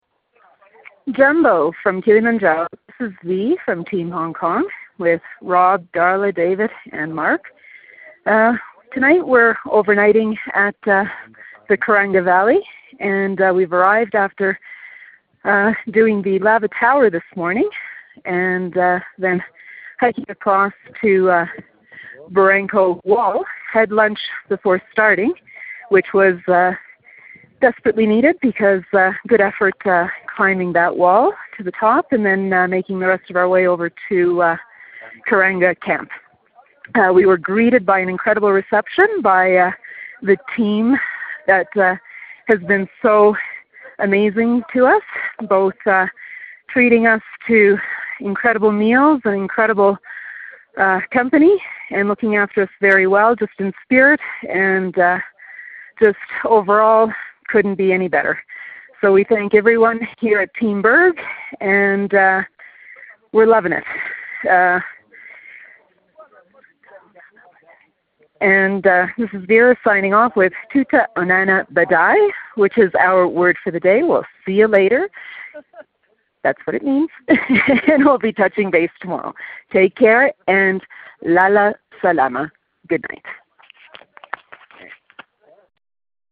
Kilimanjaro Expedition Dispatch